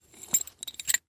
Звуки плоскогубцев
Звук перекусывания медного провода плоскогубцами